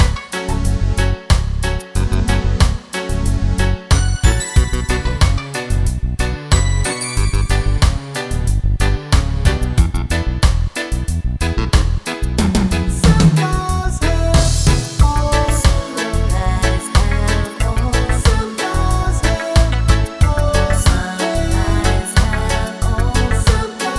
no Backing Vocals Reggae 3:39 Buy £1.50